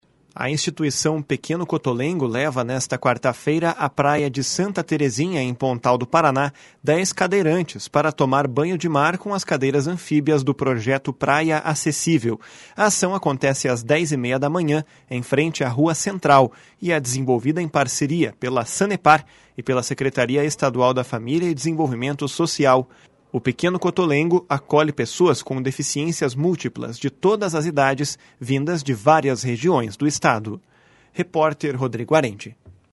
(Repórter